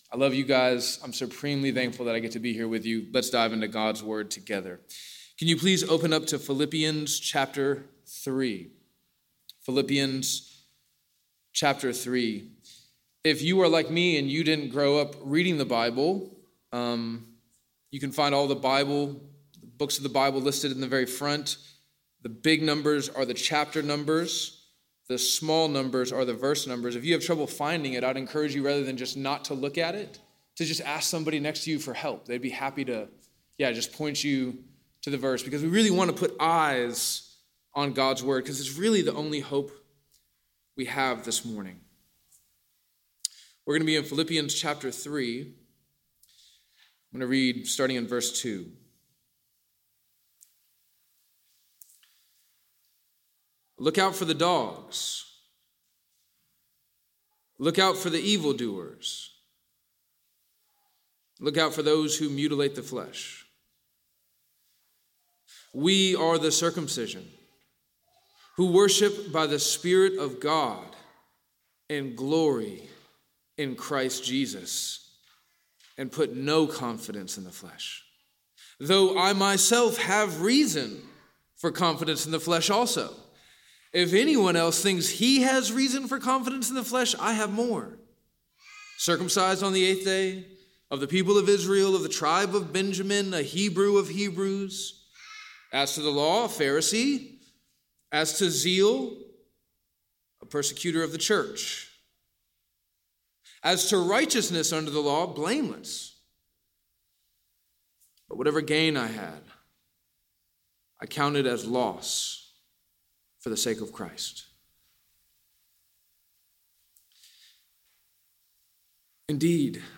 The title of this sermon is Faith Versus Flesh and was preached from Philippians 3:2-11. In this sermon we learned that God is warning you to avoid false teachers who will seek to convince you that you must trust in your flesh for your righteousness.